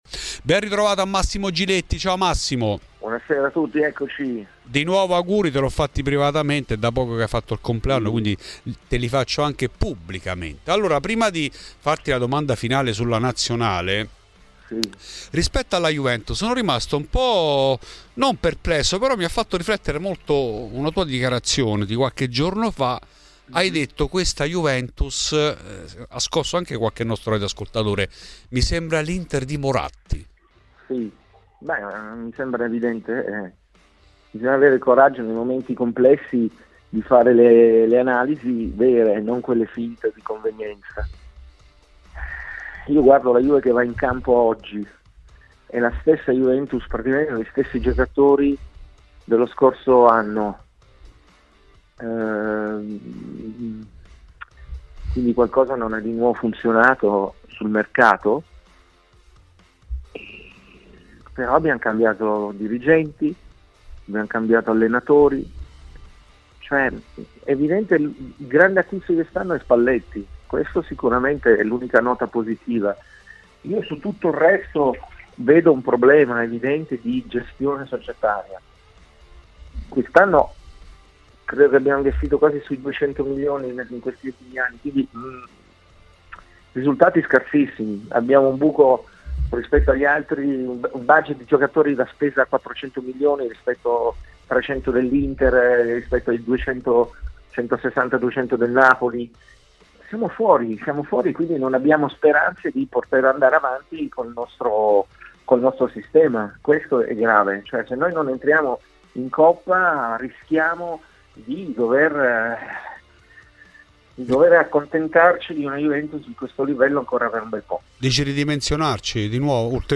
Ospite di Radio Bianconera, ai microfoni di Fuori di Juve, il giornalista e conduttore Massimo Giletti, grande tifoso della Vecchia Signora, che ha parlato delle sue ultime dichiarazioni, che hanno...